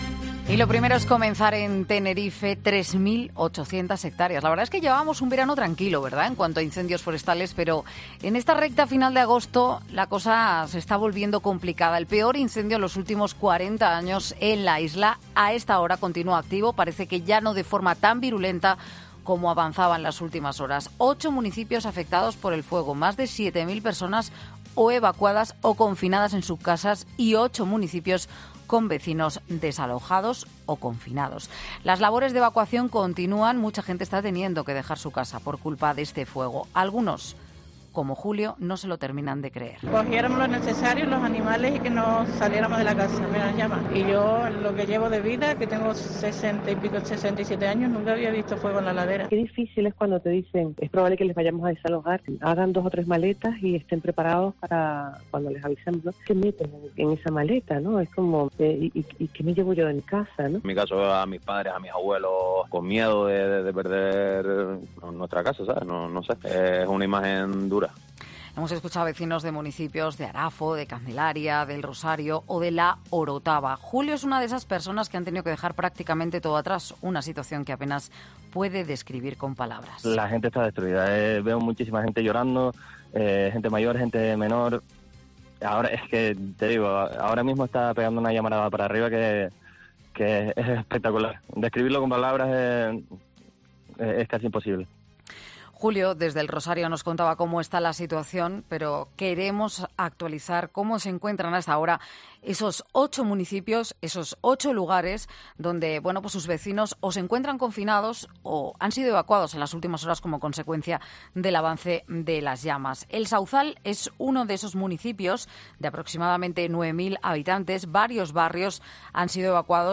Escucha a alcaldes y vecinos de Tenerife lamentándose de la situación que viven por el incendio